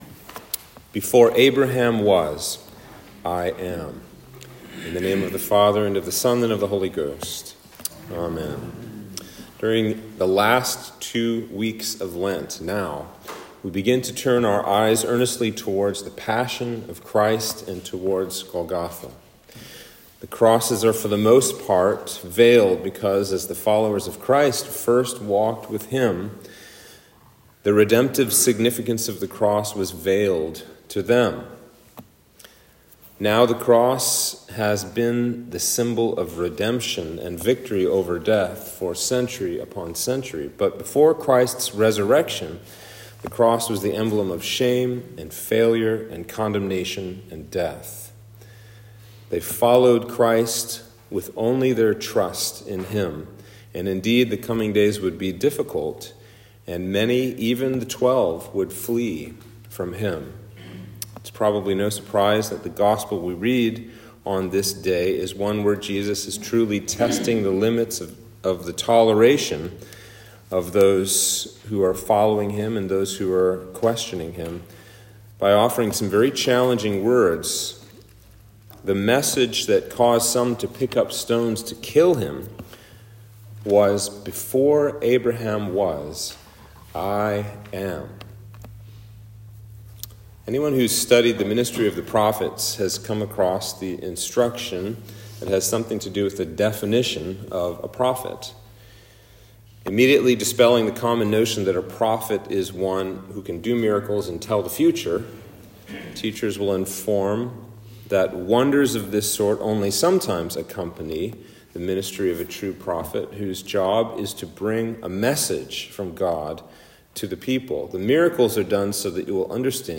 Sermon for Passion Sunday